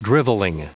Prononciation du mot drivelling en anglais (fichier audio)
Prononciation du mot : drivelling